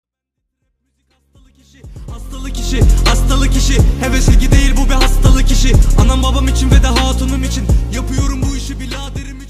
ice-cream-and-diamond-alert-mp3cut_Ddxd664.mp3